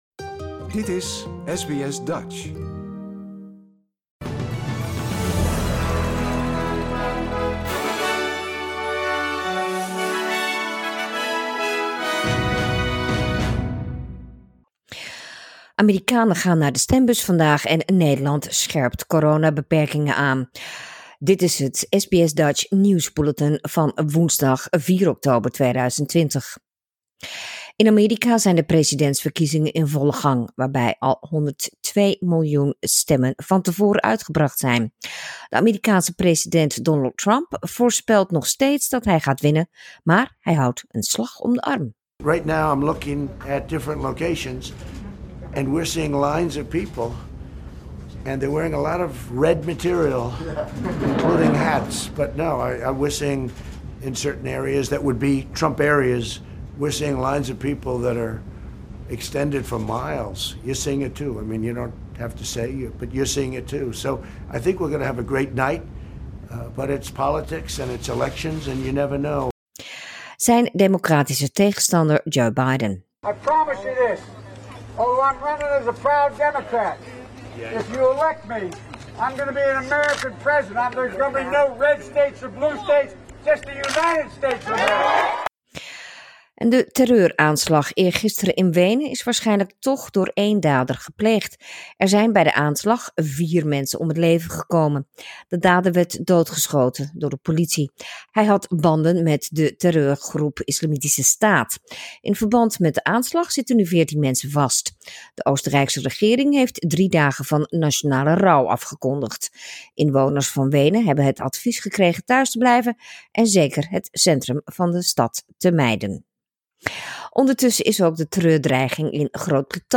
Nederlands/Australisch SBS Dutch nieuwsbulletin woensdag 4 november 2020